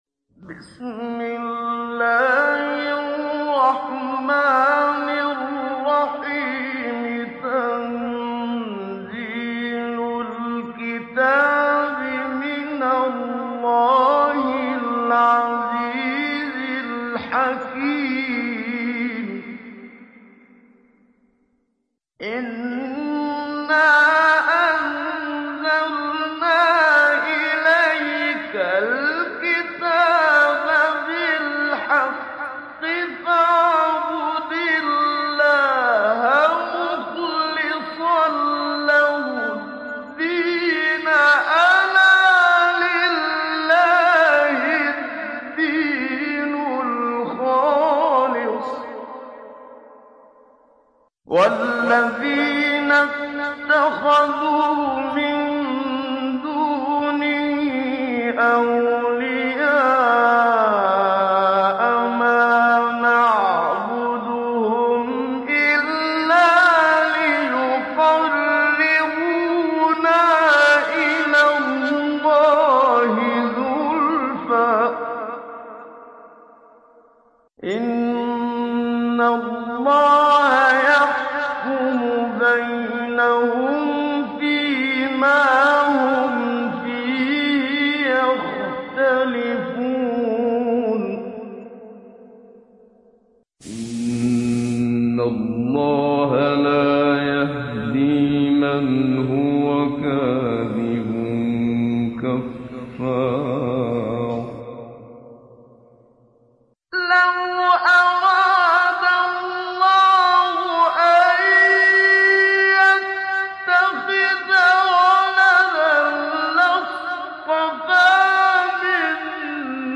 Download Surat Az zumar Muhammad Siddiq Minshawi Mujawwad